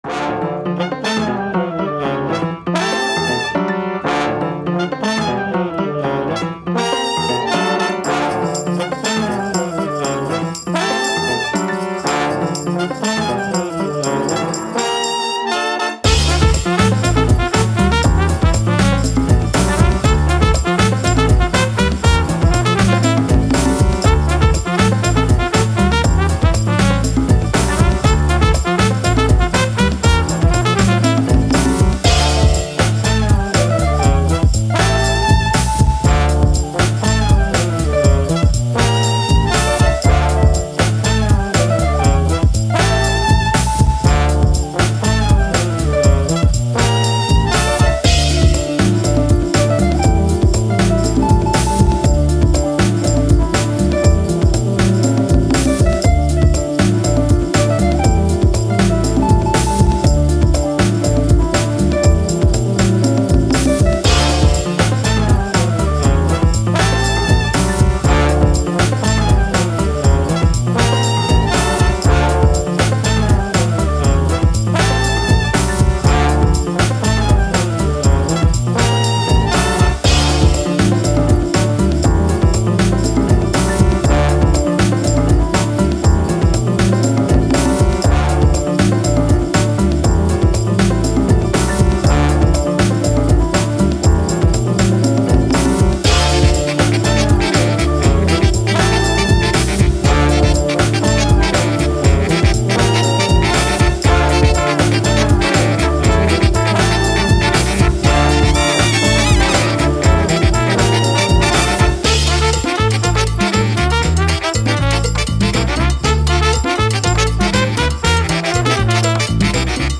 Latin, Breakbeat, Upbeat, Action, Funky